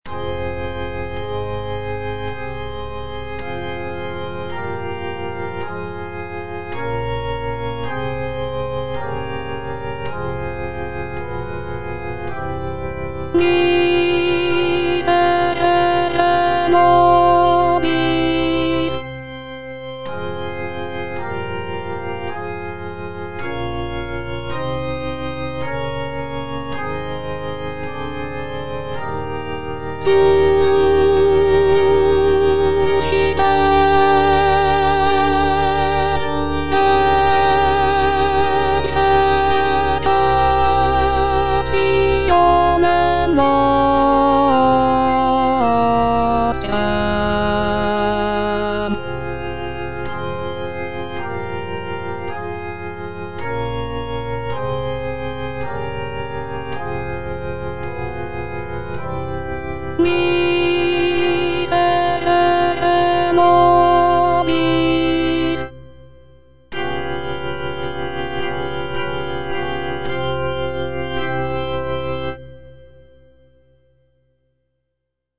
T. DUBOIS - MESSE IN F Qui tollis - Alto (Voice with metronome) Ads stop: auto-stop
It is a setting of the traditional Catholic Mass text in the key of F major. The piece is known for its lush harmonies, intricate counterpoint, and lyrical melodies.